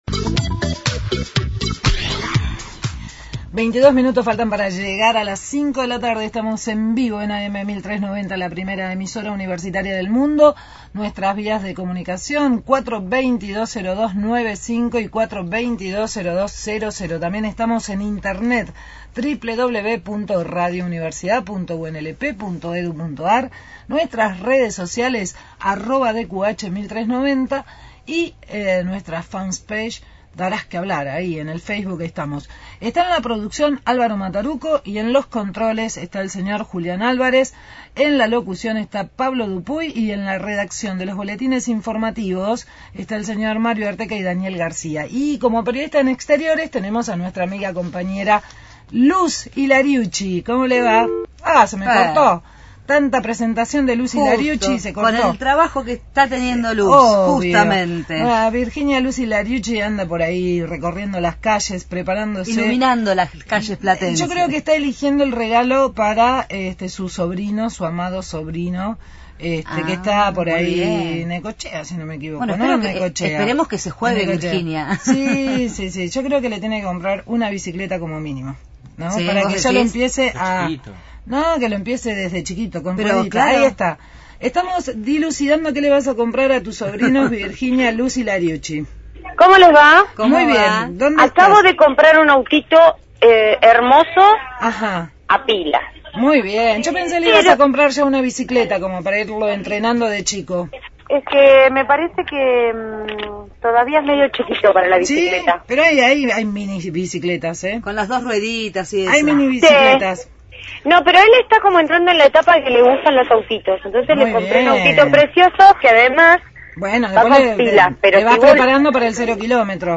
desde la Juguetería Catedral consultando precios en el fin de semana previa al Día del Niño.